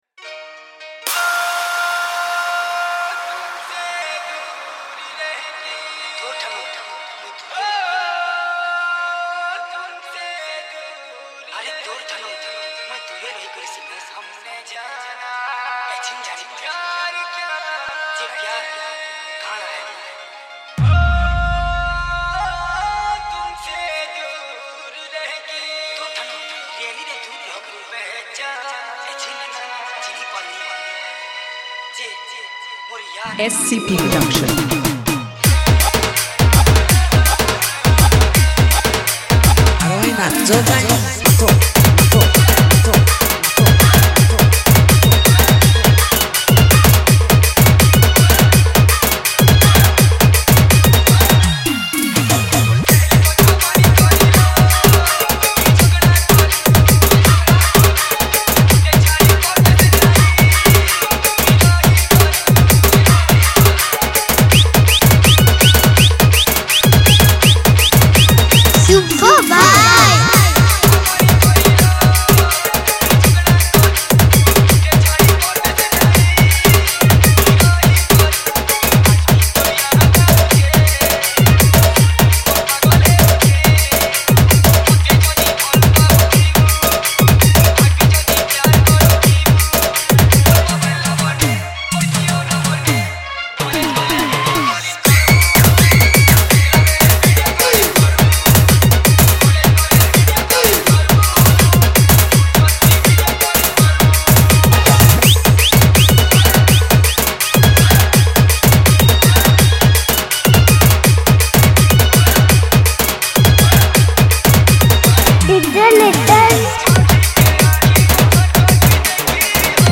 Category:  Sambalpuri New Dj Song 2019